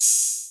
YM Open Hat 6.wav